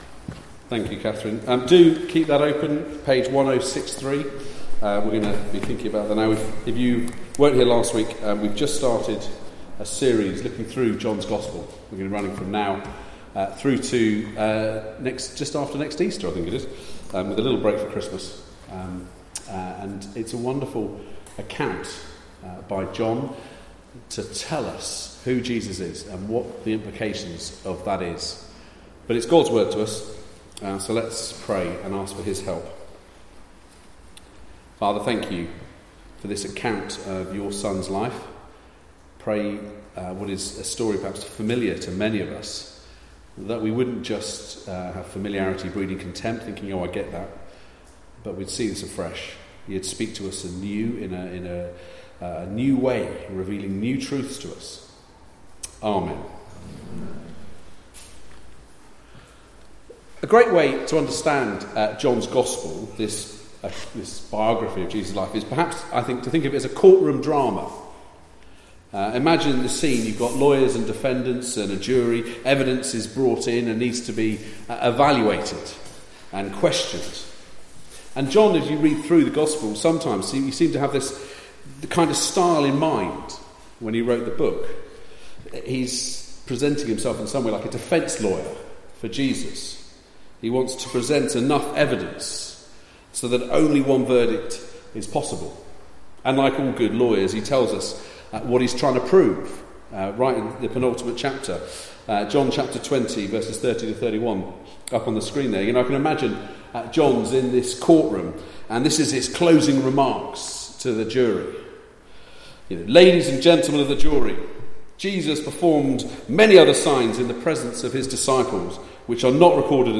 18-32 Service Type: Weekly Service at 4pm Bible Text